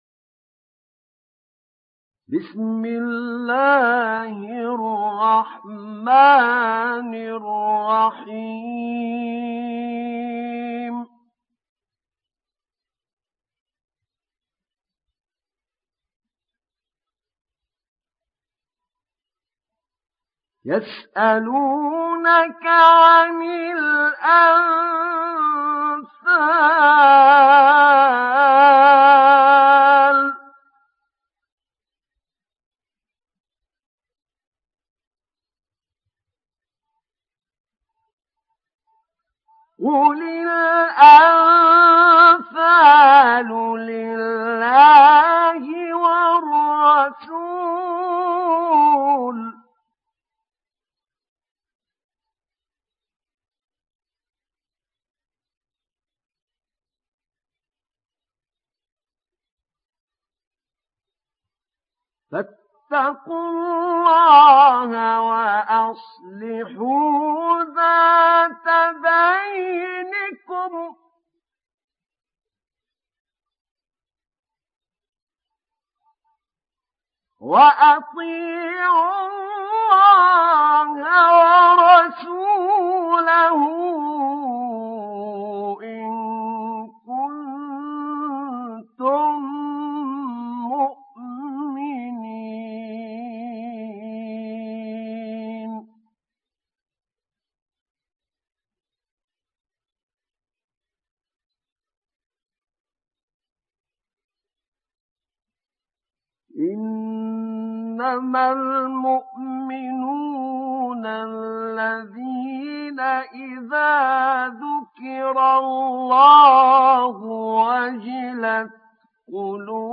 Sourate Al Anfal Télécharger mp3 Ahmed Naina Riwayat Hafs an Assim, Téléchargez le Coran et écoutez les liens directs complets mp3